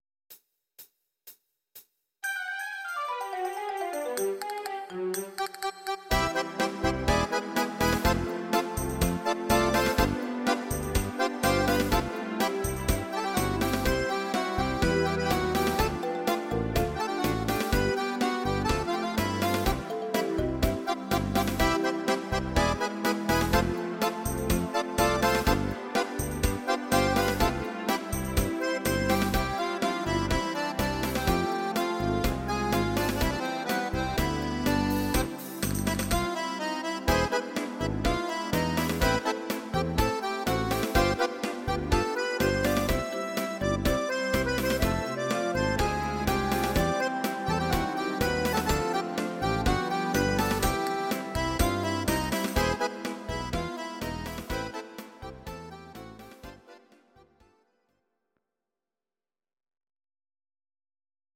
Akkordeon